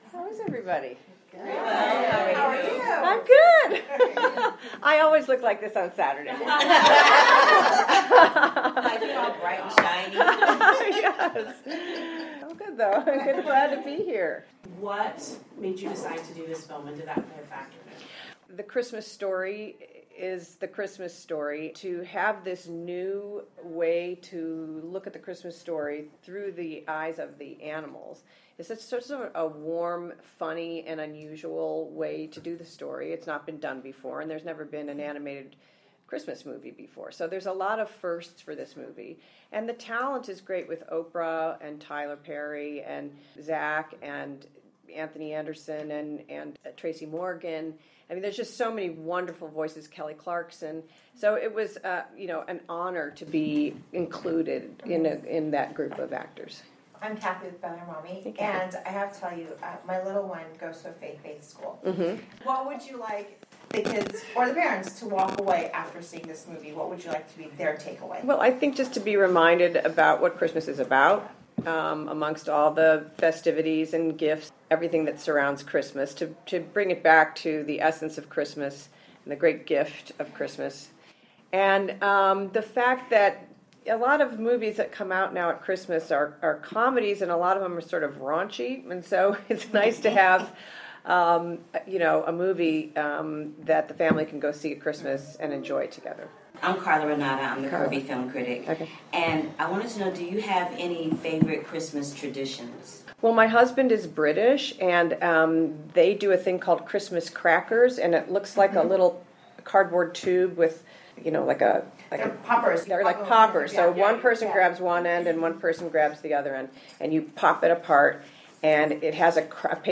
INTERVIEW WITH PATRICIA HEATON
The-Star-PatriciaHeaton-interview.mp3